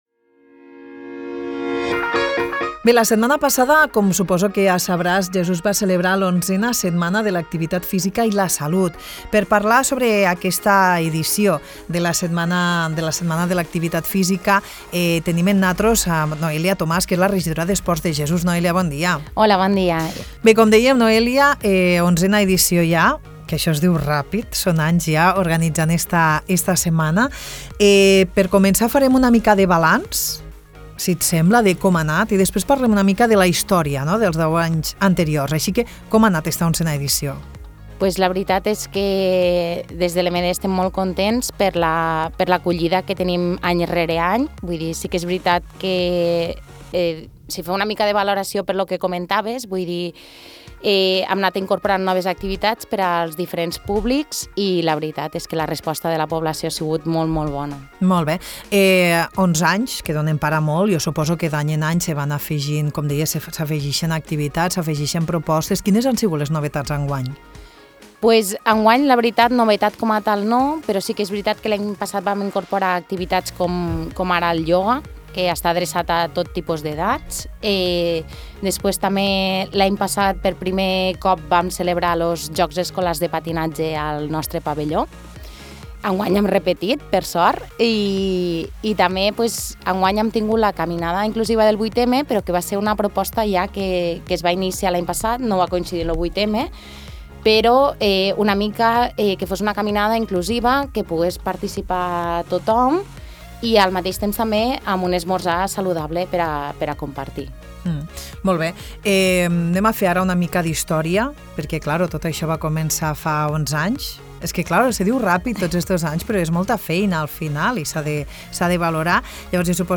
Parlem amb Noelia Tomàs, regidora d’Esports de l’EMD de Jesús, sobre l’11a Setmana de l’Activitat Física i la Salut, un esdeveniment que es va celebrar a Jesús del 27 de febrer al 8 de març. Durant els deu dies de l’edició, es van organitzar més de quaranta activitats esportives i de promoció de la salut, amb la finalitat de fomentar un estil de vida saludable.